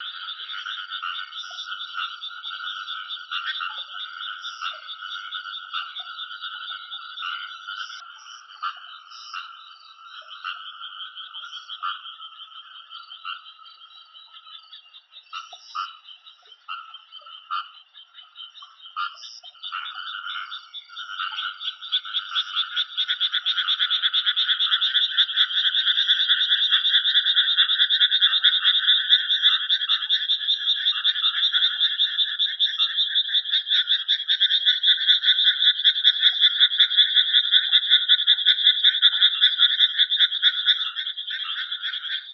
カエルの大合唱を収録。
カエルの鳴き声 着信音
私の住んでいるところは田植えの季節になると夜の田んぼにカエルと虫の鳴き声が聞こえます。癒される声です。